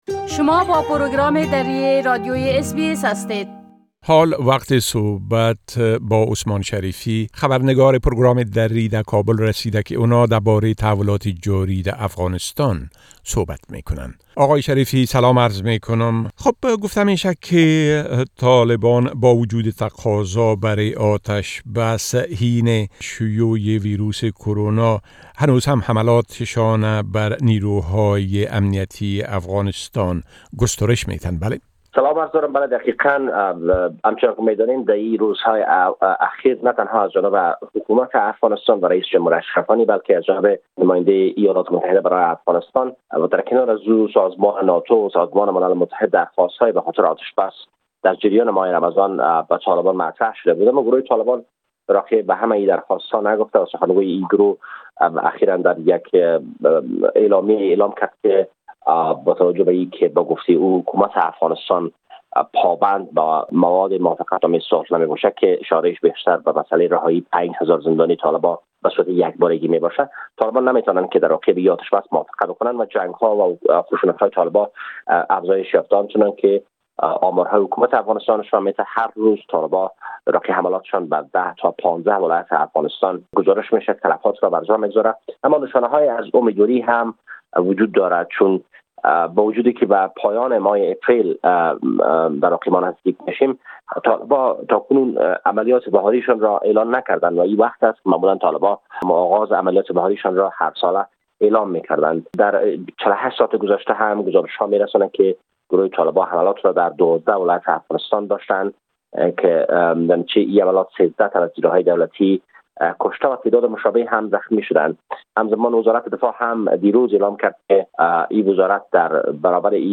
گزارش كامل خبرنگار ما در كابل بشمول اوضاع امنيتى٬ و تحولات مهم ديگر در افغانستان را در اينجا شنيده ميتوانيد.